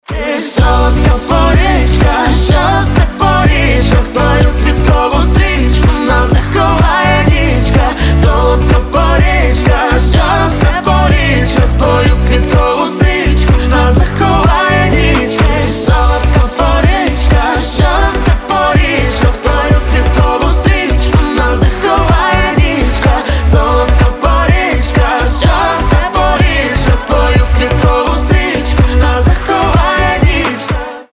• Качество: 320, Stereo
дуэт